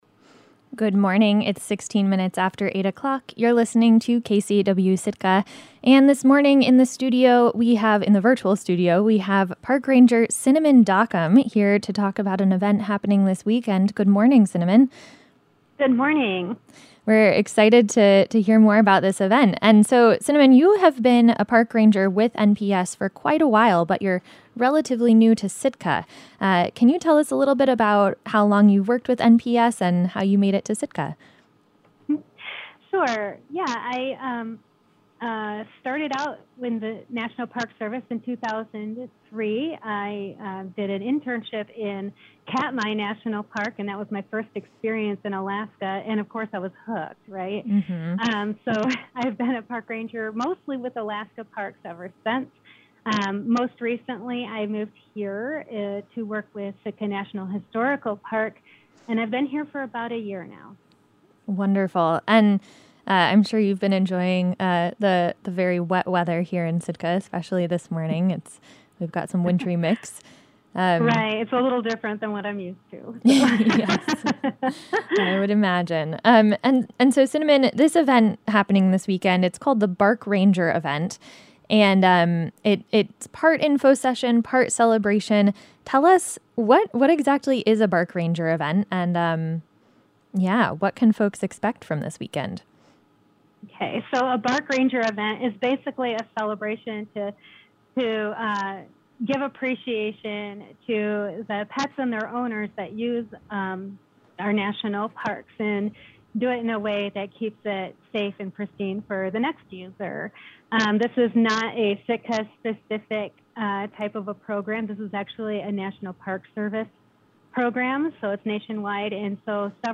Morning Interview